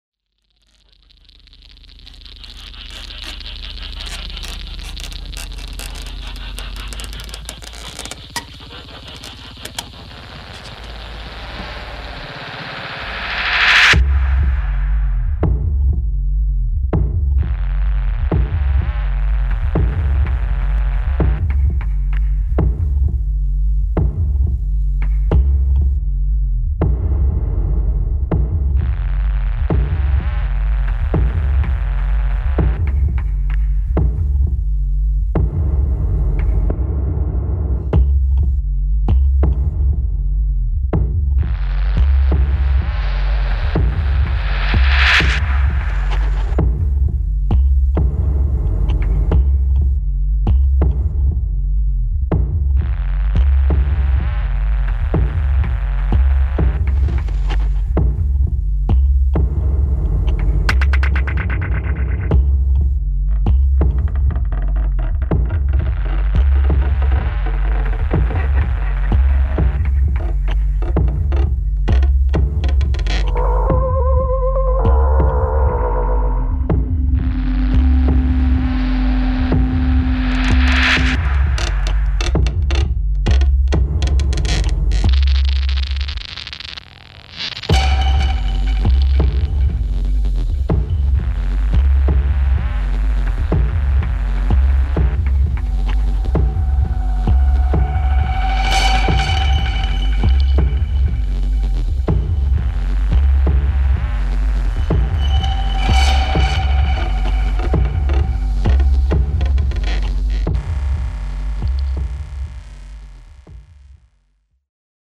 [ EXPERIMENTAL / TECHNO / DRONE ]